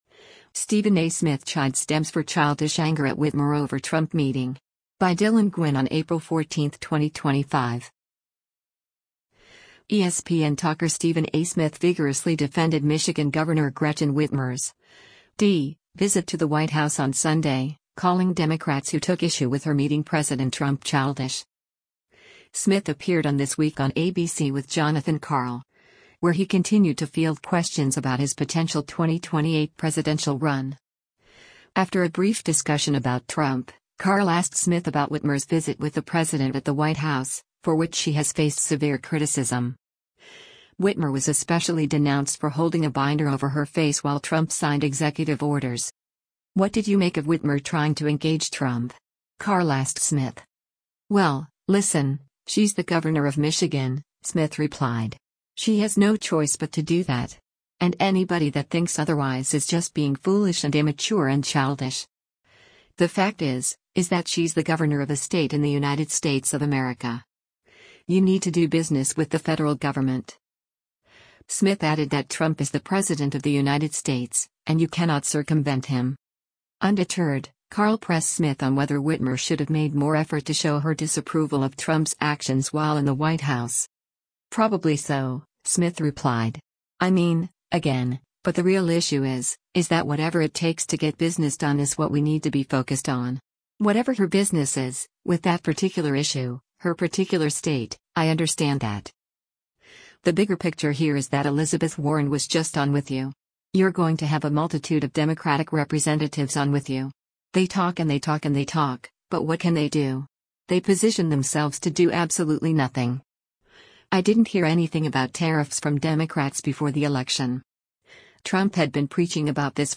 Smith appeared on This Week on ABC with Jonathan Karl, where he continued to field questions about his potential 2028 presidential run.
Karl attempted a follow-up, but Smith cut him off and continued.